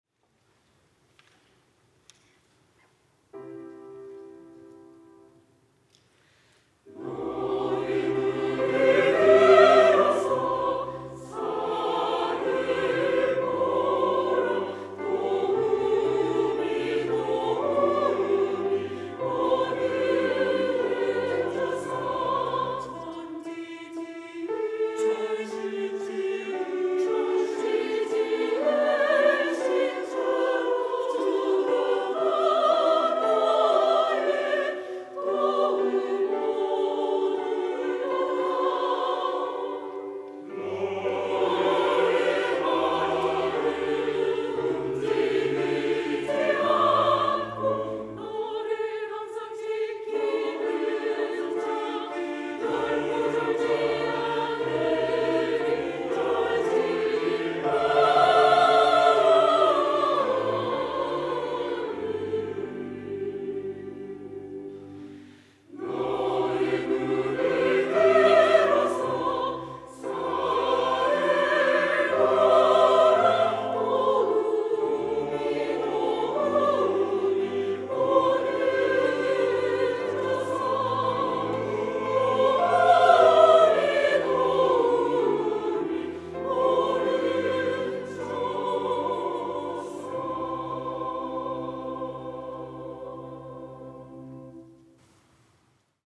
시온(주일1부) - 네 눈을 들라
찬양대